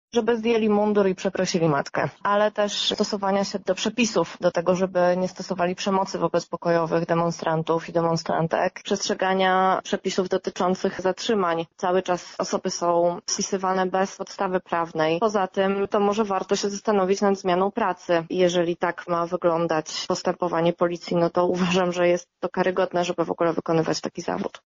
Zapytaliśmy ją, jakie są oczekiwania aktywistów wobec funkcjonariuszy: